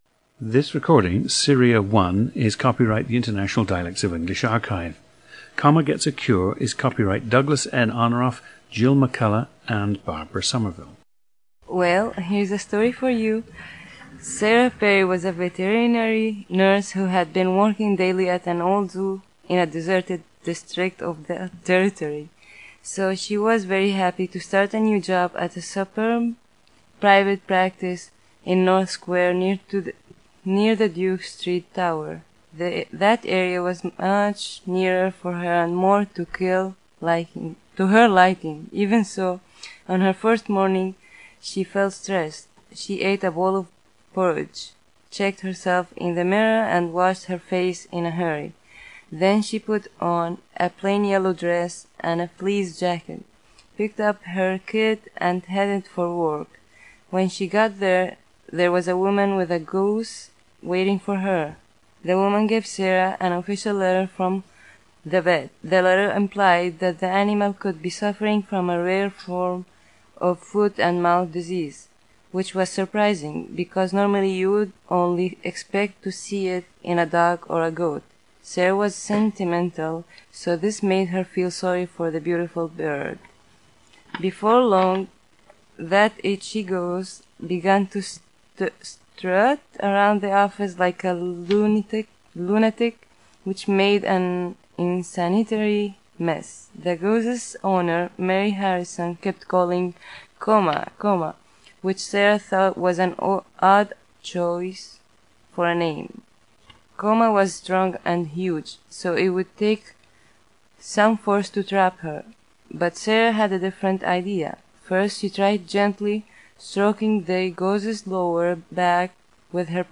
GENDER: female
OTHER INFLUENCES ON SPEECH:
Her first language is Spanish, and she did not learn Arabic until she moved to Saudi Arabia at age 11.
• Recordings of accent/dialect speakers from the region you select.